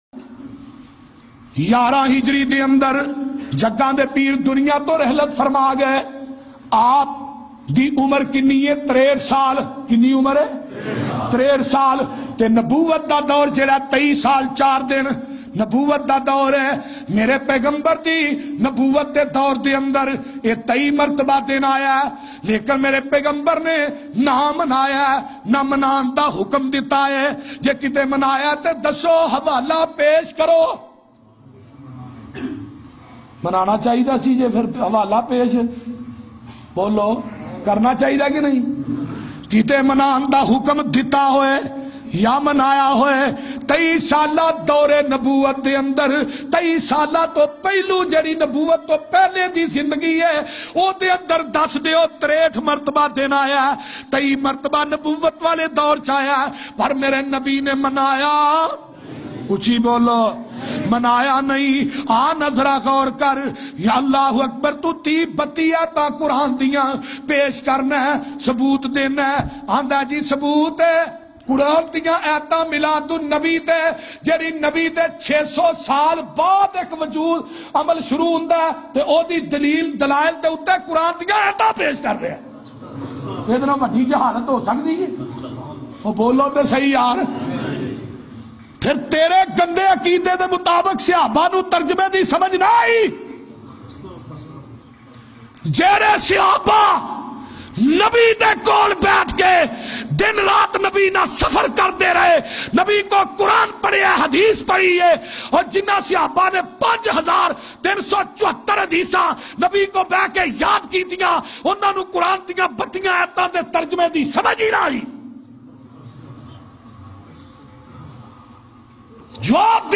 Jashan Eid Milad Un Nabi Ki Haqiqat bayan mp3